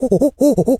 monkey_chatter_17.wav